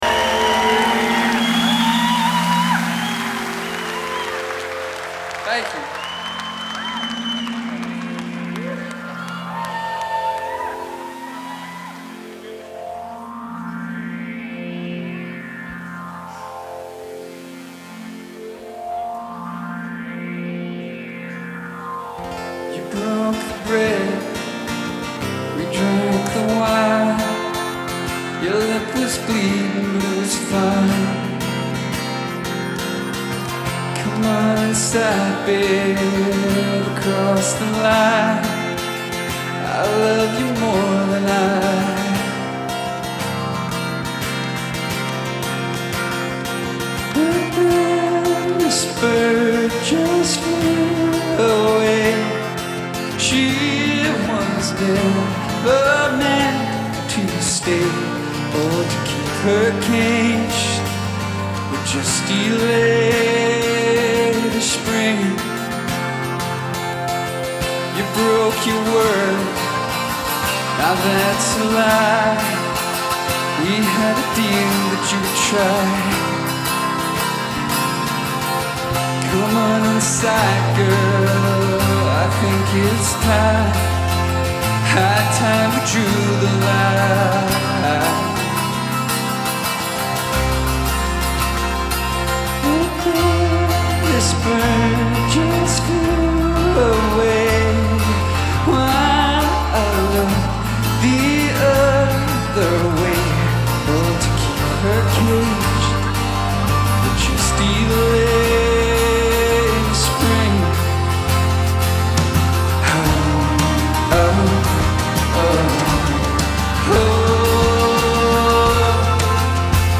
Genre : Alternatif et Indé